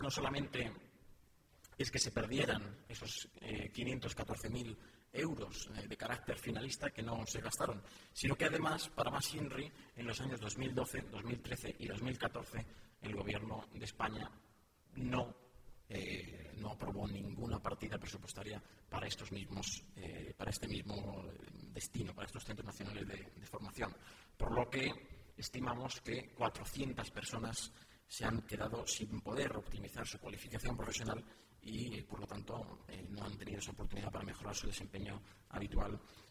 portavoz_gobierno_-_centros_formacion_profesional.mp3